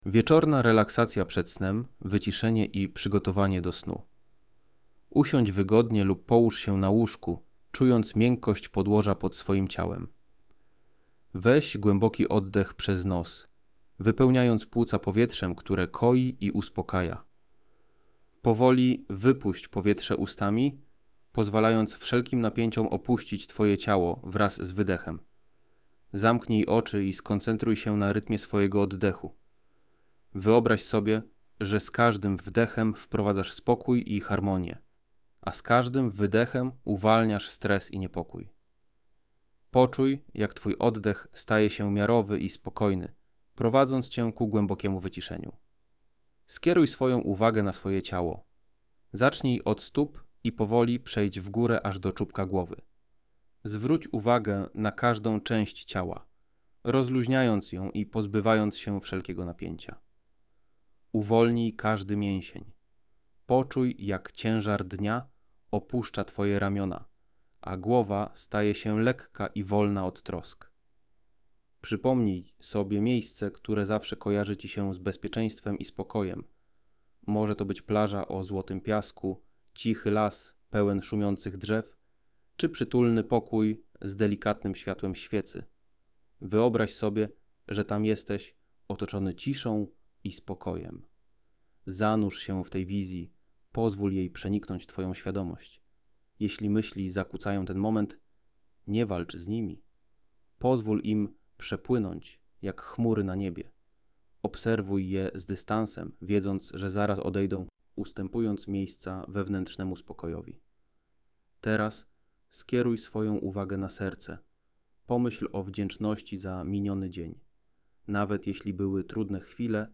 Krótkie, uspokajające nagranie medytacyjne, które pomaga wyciszyć umysł, zredukować stres i przygotować ciało do spokojnego snu.
Medytacja Wieczorna – Relaksacja przed snem, wyciszenie i przygotowanie do snu to łagodne, krótkie nagranie prowadzone, zaprojektowane po to, aby pomóc Ci zakończyć dzień w atmosferze spokoju i wewnętrznej harmonii.
• Audiobook MP3 – profesjonalne nagranie medytacji wieczornej,